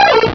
Cri de Canarticho dans Pokémon Rubis et Saphir.